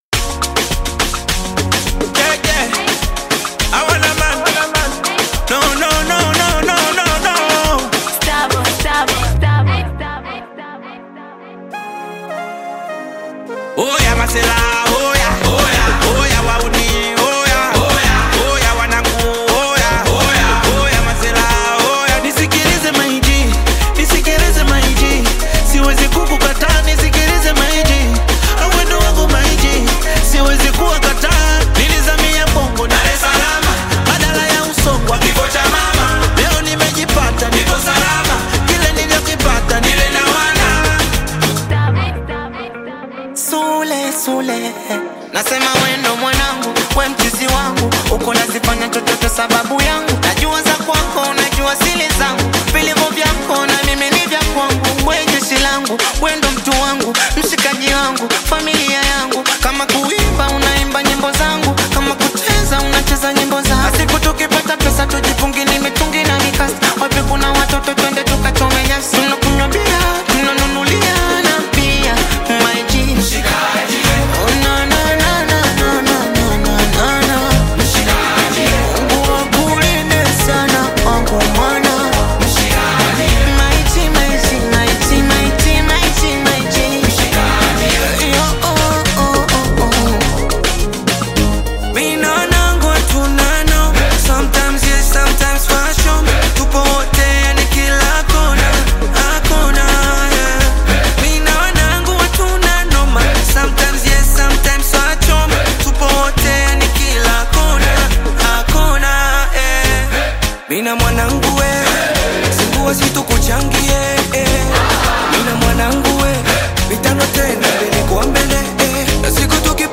official remix